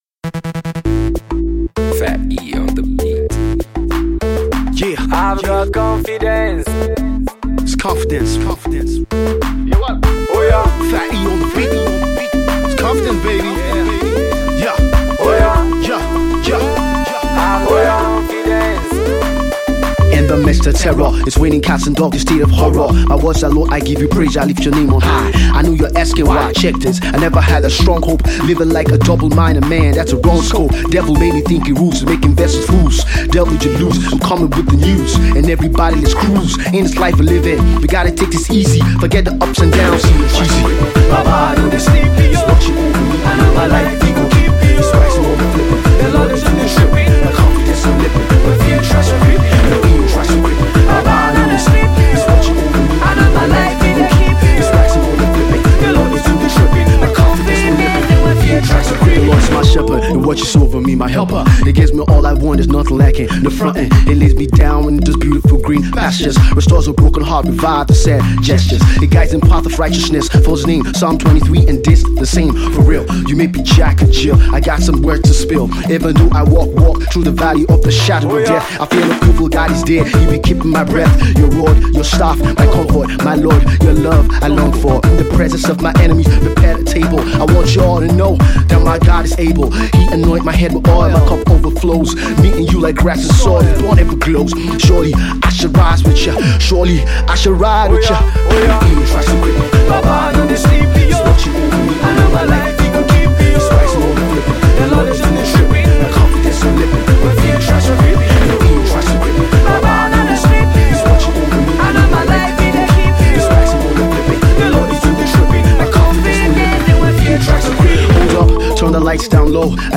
Afro/Hip Hop joint